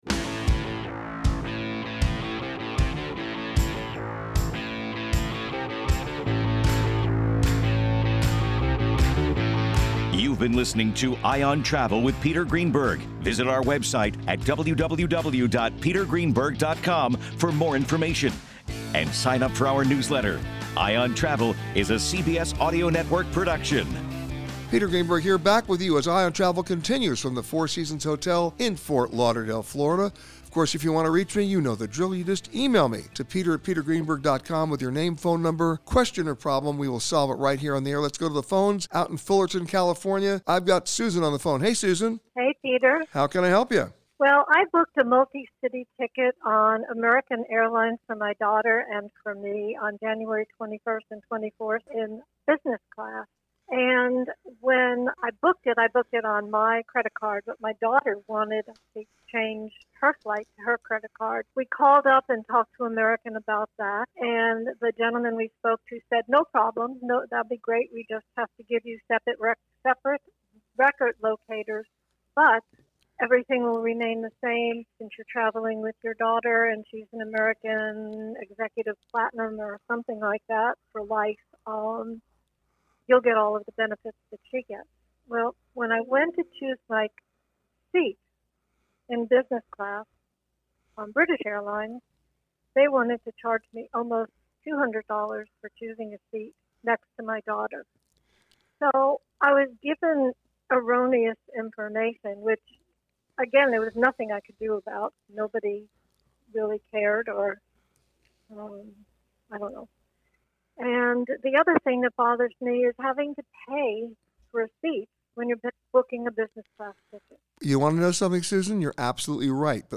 This week, Peter answered your questions from Four Seasons Hotel in Fort Lauderdale, Florida. Peter answers your questions on selecting seats on airplanes, river cruises, group rates on airline tickets and more. Each week during our CBS Radio Show, Eye on Travel, Peter answers your travel questions.